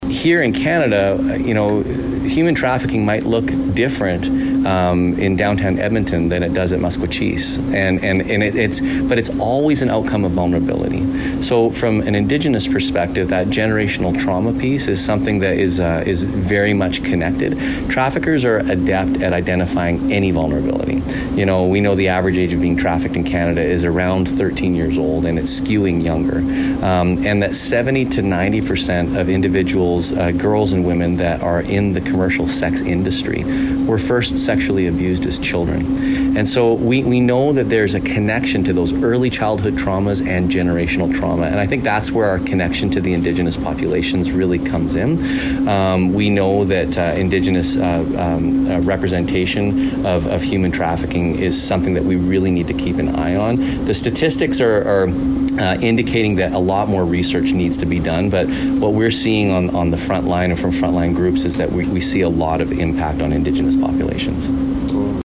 89.3 the Raven Spoke with Paul Brandt, CEO & Founder of Not My City, and he speaks up on the current state of human trafficking in Canada and the relation with Indigenous peoples with the safe room.